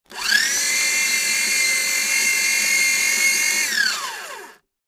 Paper Shredder
Paper shredder destroys documents.